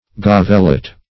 Search Result for " gavelet" : The Collaborative International Dictionary of English v.0.48: Gavelet \Gav"el*et\, n. [From Gavel tribute.]